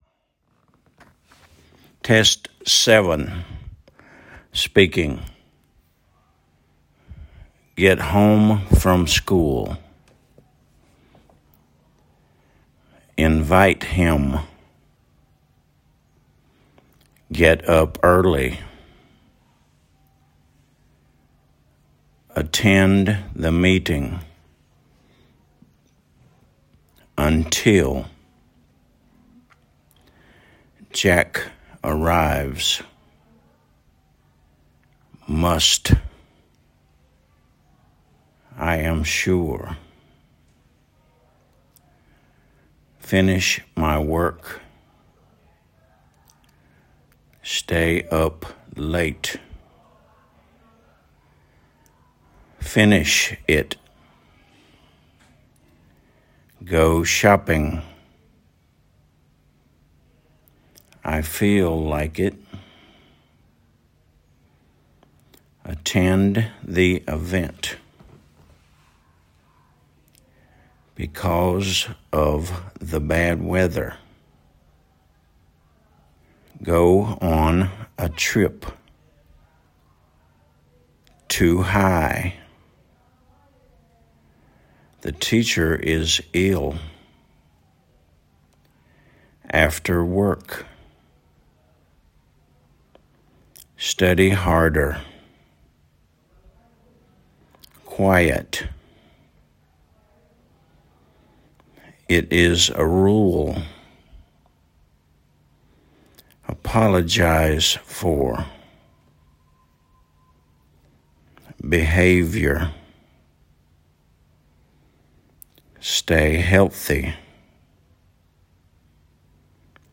invite him /ɪnˈvaɪt hɪm/
attend the meeting /əˈtɛnd ðə ˈmiːtɪŋ/
The teacher is ill. /ðə ˈtiːʧə ɪz ɪl/
leave the party early /liːv ðə ˈpɑːti ˈɜːli/